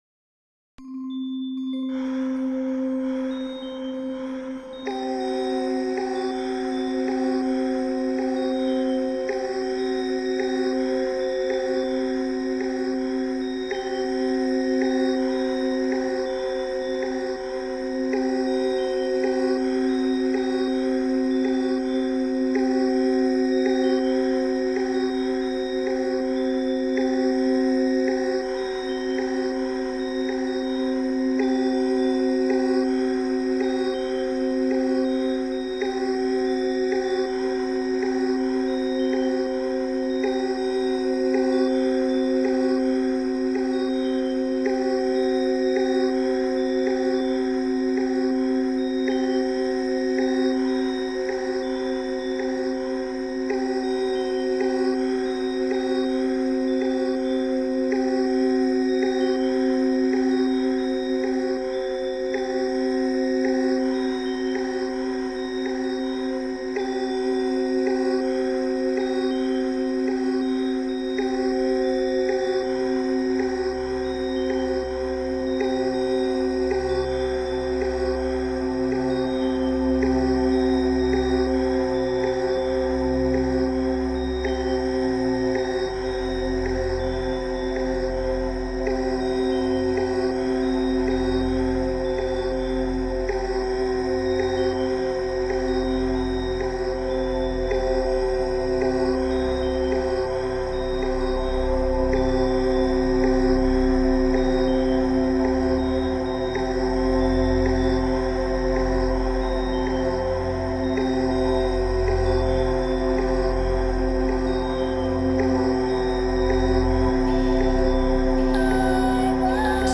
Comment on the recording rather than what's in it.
100 % Vinyl mix